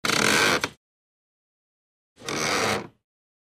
Звуки скрипа кровати
Перекатываемся на бок и обратно